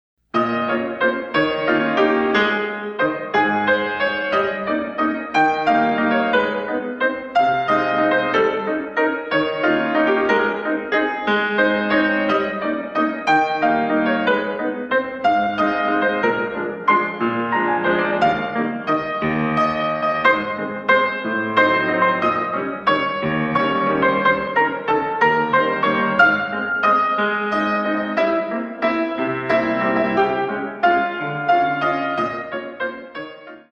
128 Counts